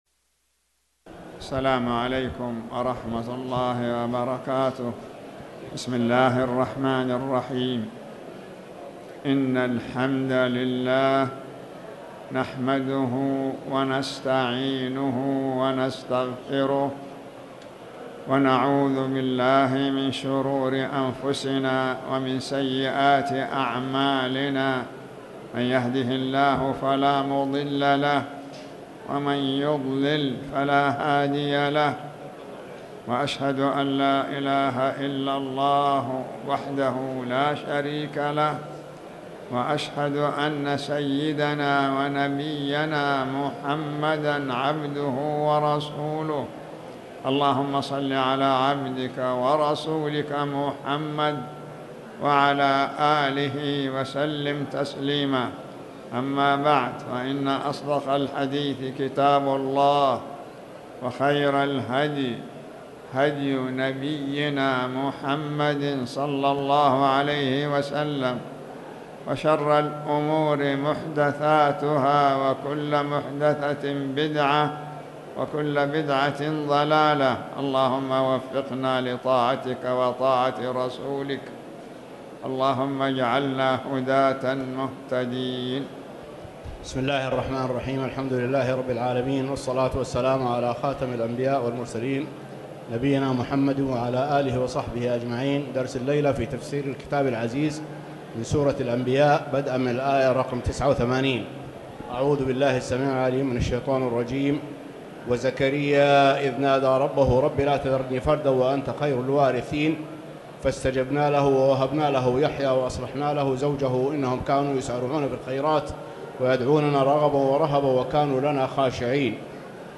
تاريخ النشر ٢ ربيع الأول ١٤٣٩ هـ المكان: المسجد الحرام الشيخ